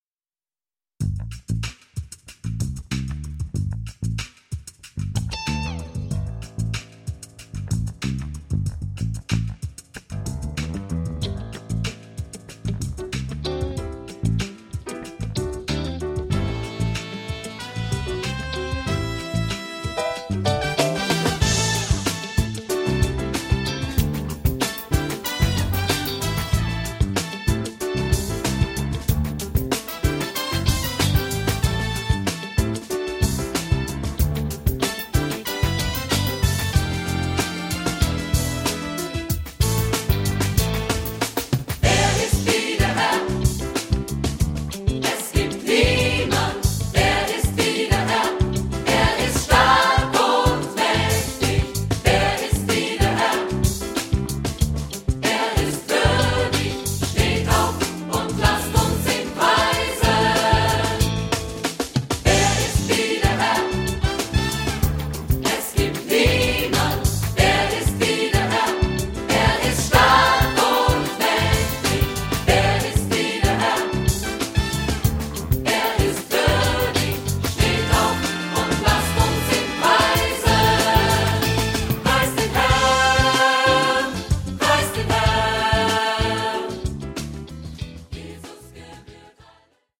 • Stil/Genre: modern
• Sachgebiet: Praise & Worship